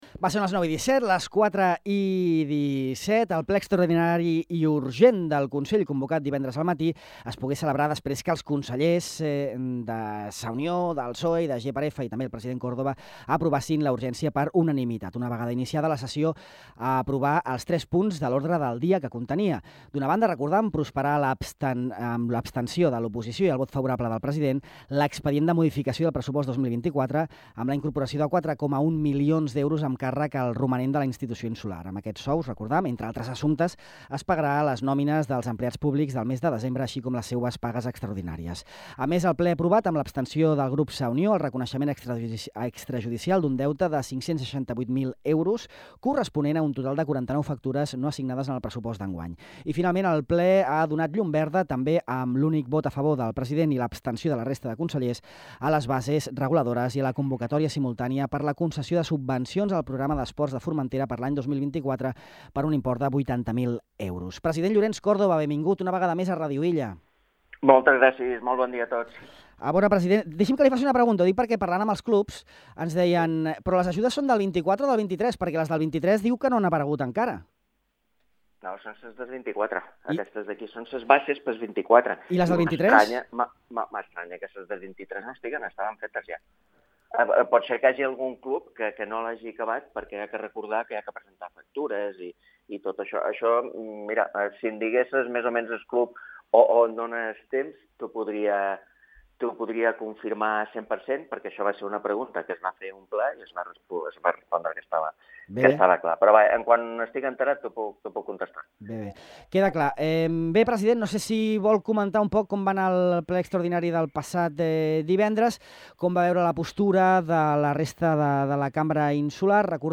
El president Llorenç Córdoba, Rafael Ramírez (PSOE), Rafa González (GxF) i José Manuel Alcaraz (Sa Unió) opinen sobre el ple extraordinari i urgent que es va portar a terme el passat divendres després que s’aprovàs per unanimitat la urgència de la sessió.